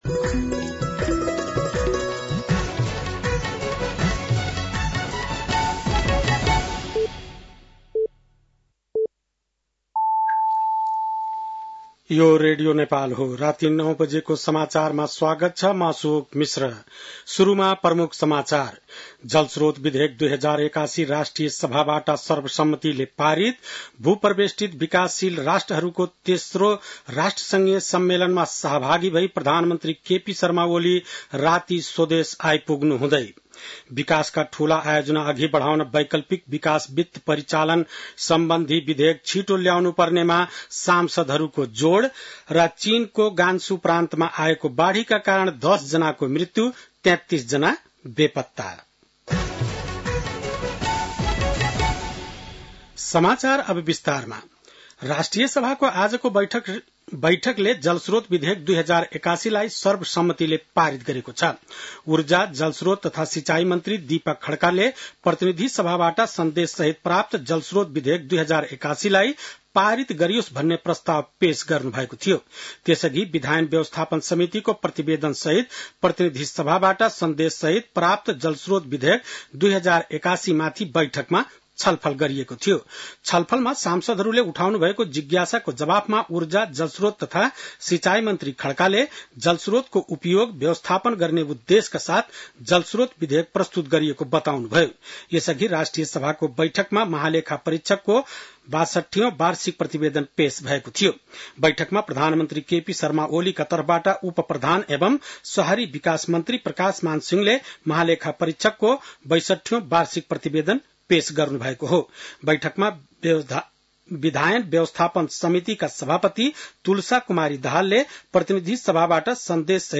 बेलुकी ९ बजेको नेपाली समाचार : २३ साउन , २०८२
9-pm-nepali-news-4-23.mp3